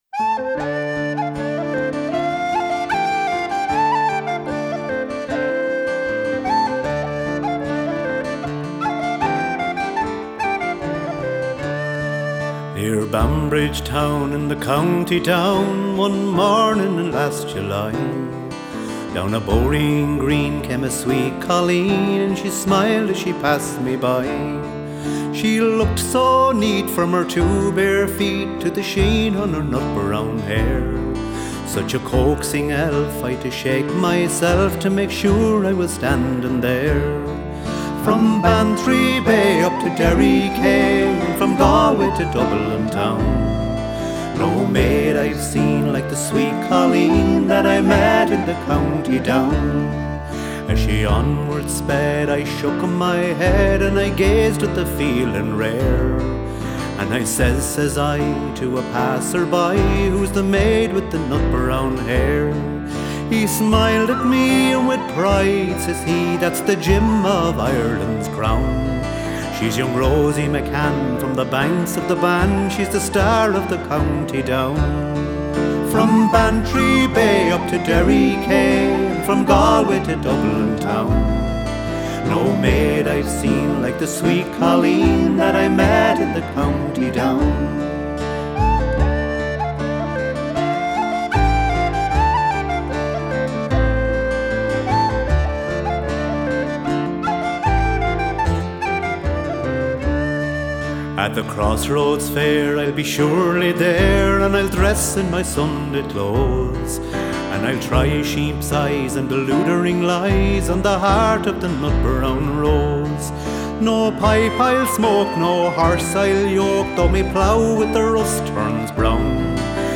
Genre: Folk / World /Celtic